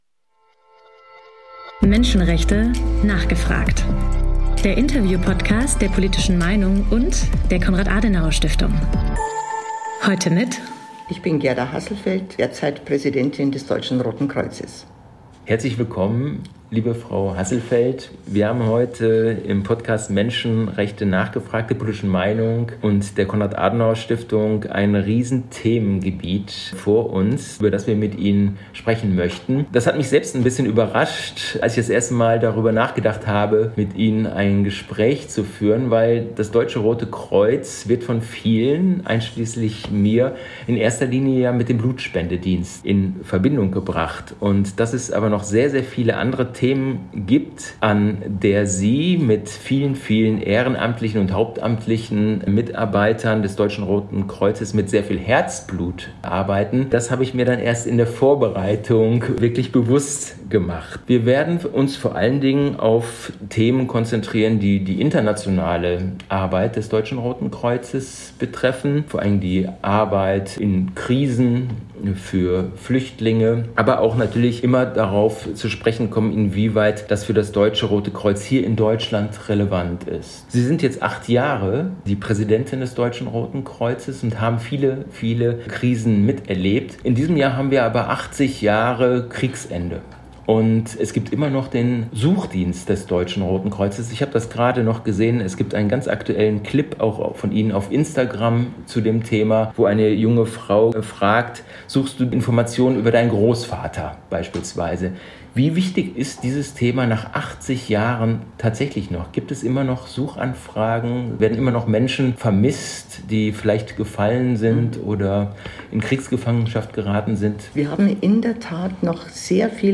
Gerda Hasselfeldt zu Gast im Podcast Menschenrechte: nachgefragt der Zeitschrift Die Politische Meinung und der Konrad-Adenauer-Stiftung.